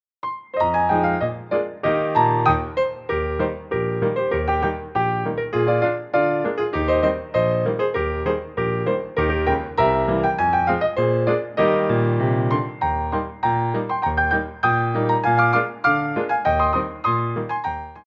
2/4 (8x8)